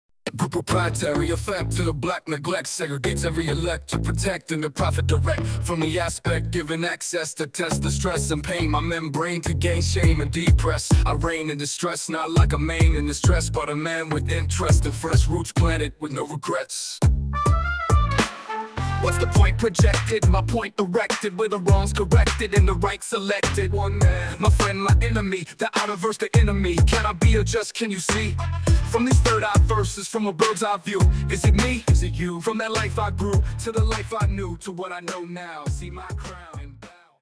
Short version of the song, full version after purchase.
An incredible Hip Hop song, creative and inspiring.